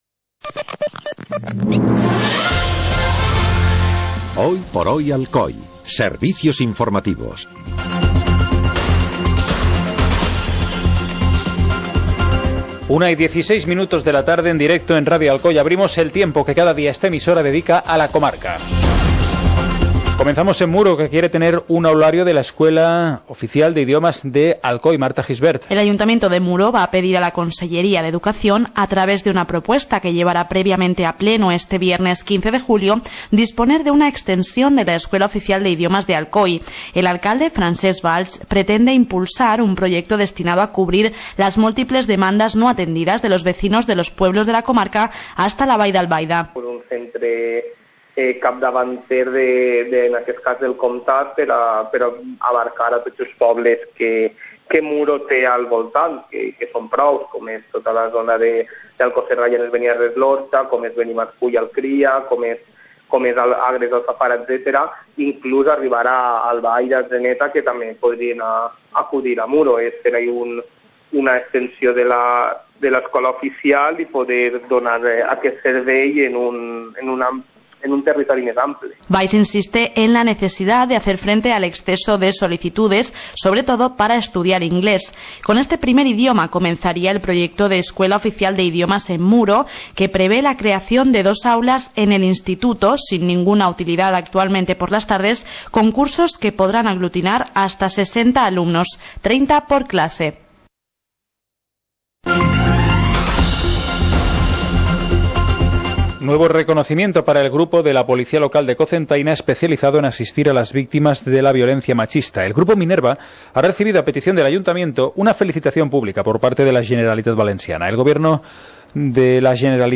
Informativo comarcal - jueves, 14 de julio de 2016